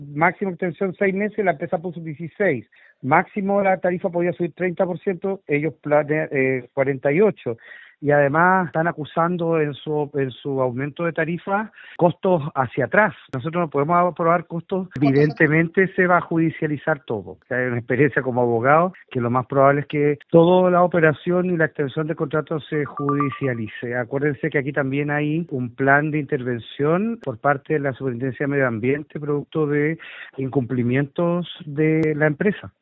En conversación con Radio Bío Bío, el vicepresidente de la Asociación y alcalde de Calbuco, Marco Silva, explicó que decidieron operar el relleno entre las comunas porque la compañía había solicitado casi un 50% más de dinero.